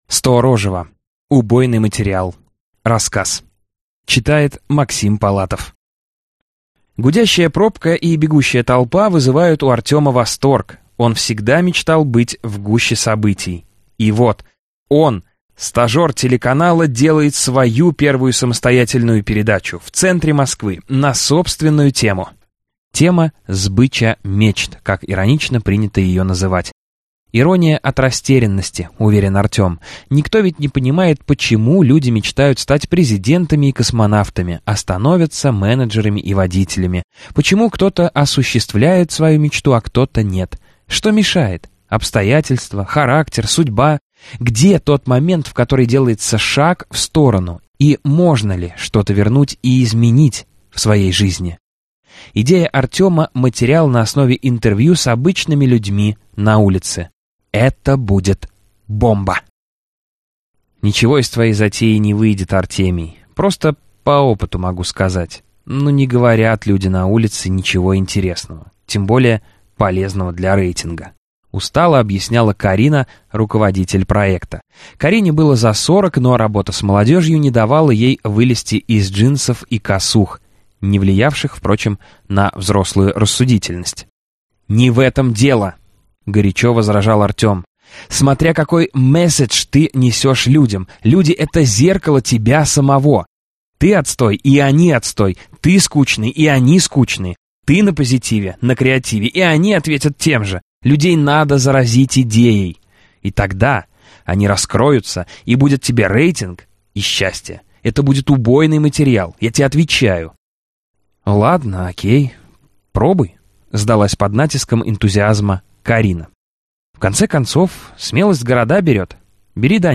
Аудиокнига Убойный материал | Библиотека аудиокниг